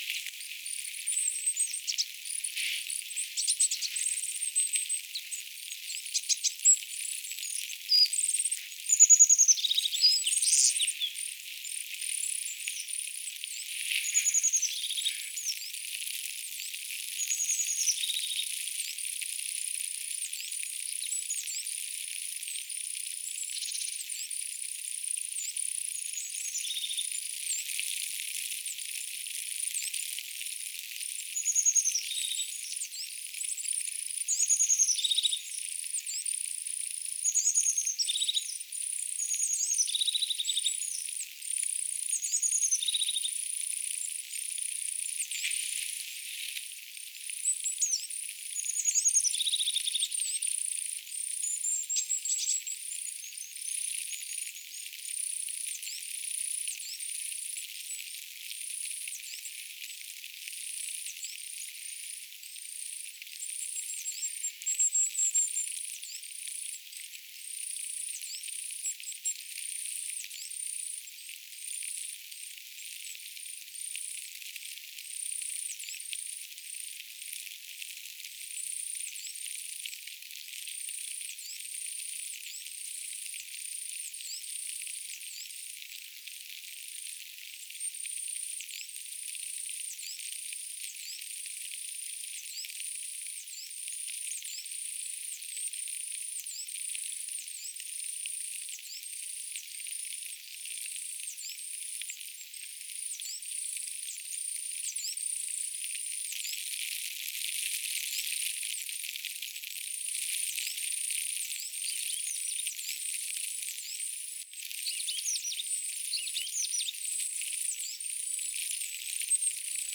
kuusitiaisen siritysta 5 minuuttia
yhtä soittoa
Ensin ilmeisesti tämä sirittävä koiras
ja alkoi tämä siritys,
päätellen sirityksen innokkuudesta.
kuusitiainen_ilm_sirittaa_oikein_antaimuksella_naaraalle_joka_sita_paljon_miellyttaa.mp3